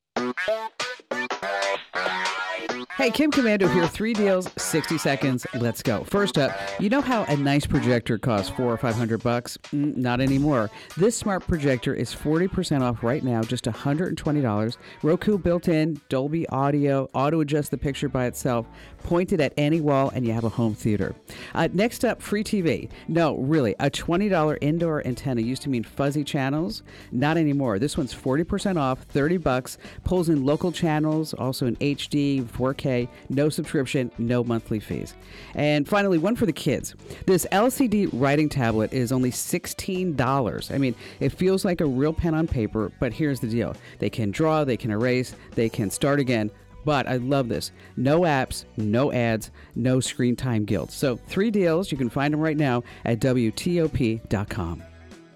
Kim Komando breaks down the latest hot deals.